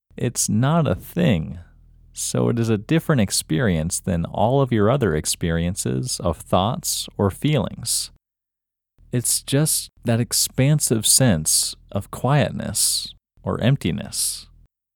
Locate IN English Male 14